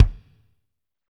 Index of /90_sSampleCDs/Northstar - Drumscapes Roland/DRM_Pop_Country/KIK_P_C Kicks x